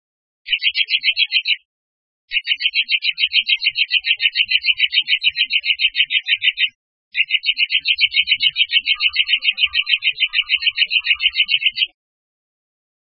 〔ゴジュウカラ〕フィフィフィフィ／シチピ，シチピ，シチピ／山地の落葉広葉樹林に